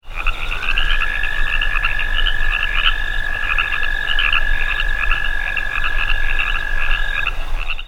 Arroyo Toad - Anaxyrus californicus
One short call